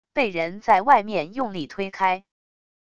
被人在外面用力推开wav音频